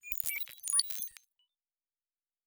pgs/Assets/Audio/Sci-Fi Sounds/Electric/Data Calculating 3_3.wav at master
Data Calculating 3_3.wav